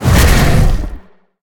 File:Sfx creature hiddencroc chase os 02.ogg - Subnautica Wiki
Sfx_creature_hiddencroc_chase_os_02.ogg